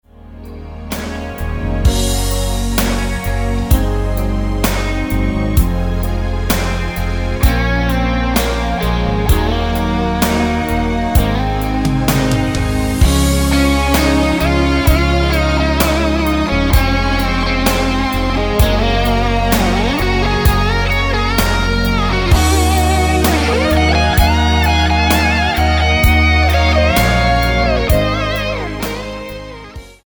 Tonart:C# ohne Chor